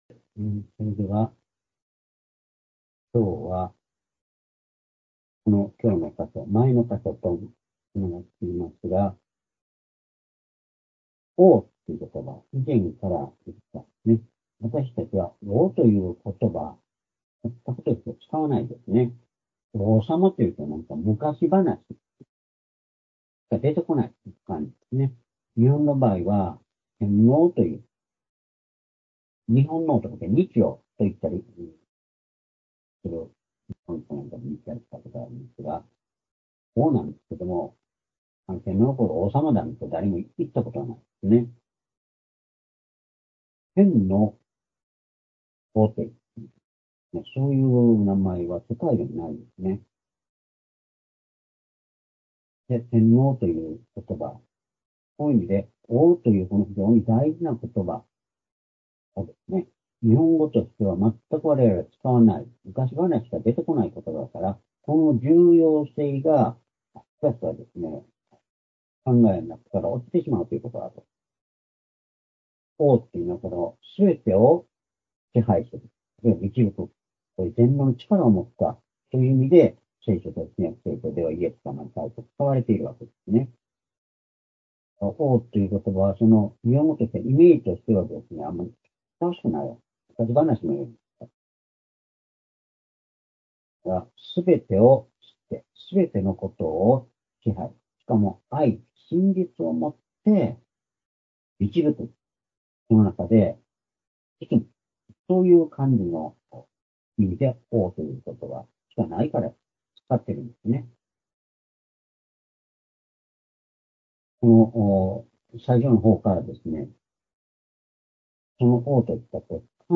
主日礼拝日時 ２０２４年１２月８日（主日礼拝） 聖書講話箇所 「十字架と王なるキリスト」 ヨハネ19章１２節～１６節 ※視聴できない場合は をクリックしてください。